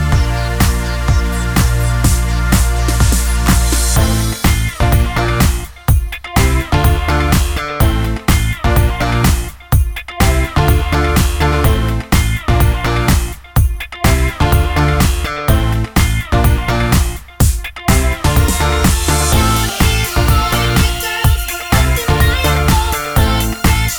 No Rapper Pop (2000s) 3:54 Buy £1.50